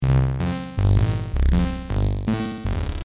描述：编辑嗡嗡声的声音
标签： 巴兹 噪声 电子 机械
声道立体声